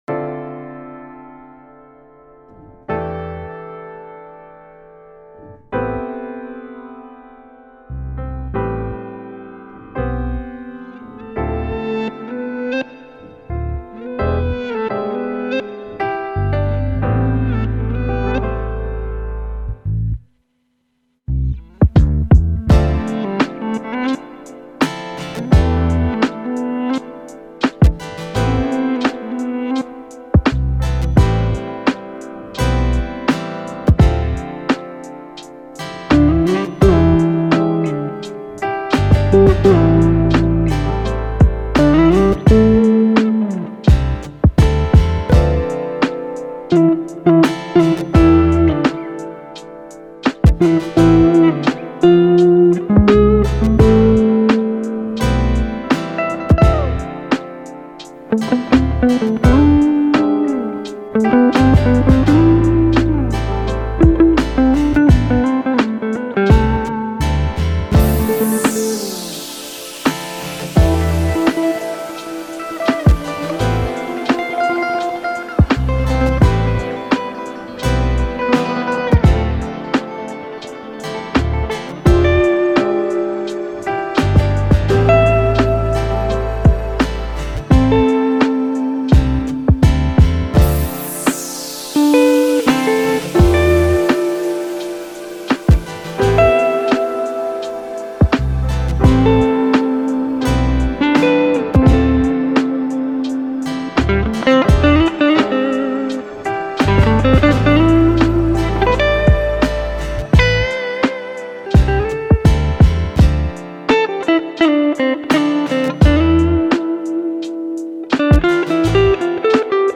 Session Lecture : Ambiance Étude